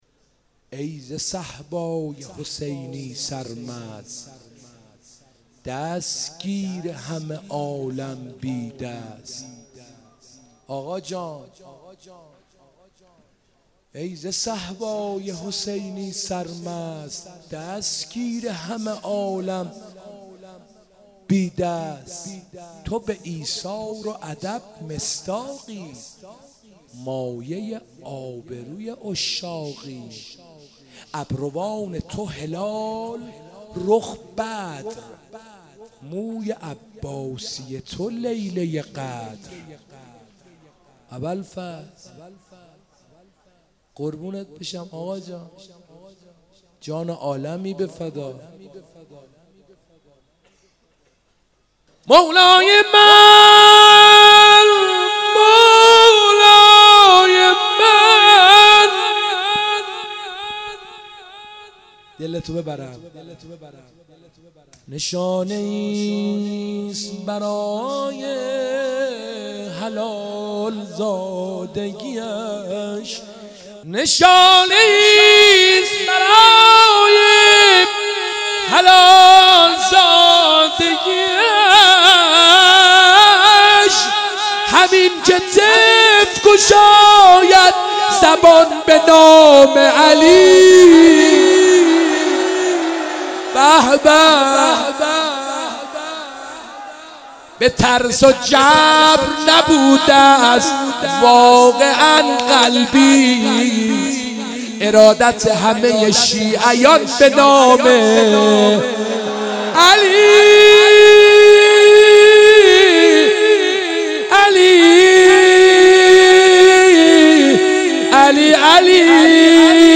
شور/رفتی
شعرخوانی